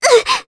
Ophelia-Vox_Damage_jp_02.wav